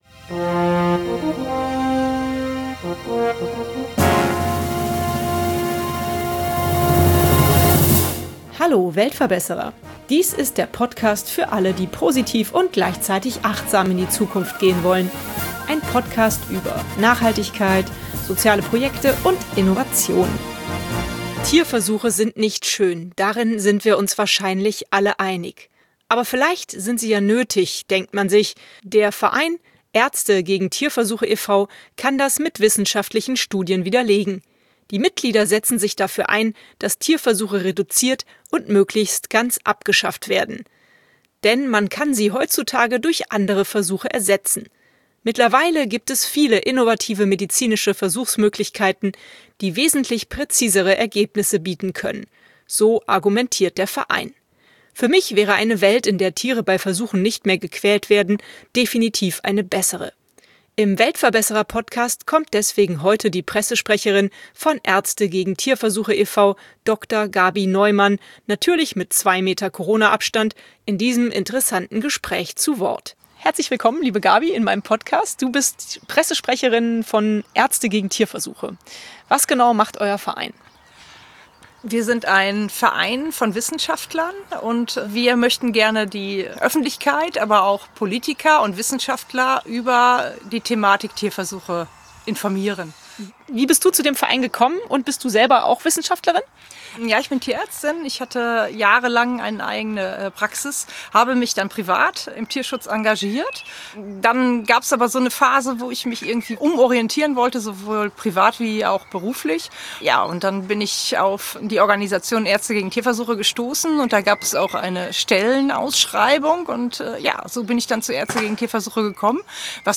natürlich mit 2Meter-Corona-Abstand, in diesem interessanten Gespräch zu Wort